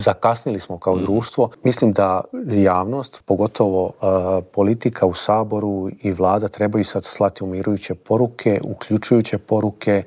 Predsjednik Srpskog narodnog vijeća Boris Milošević u Intervjuu tjedna Media servisa poručuje da je teško objasniti kako je iz jedne male kulturne priredbe ovo preraslo u tako veliki problem.